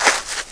SAND 4.WAV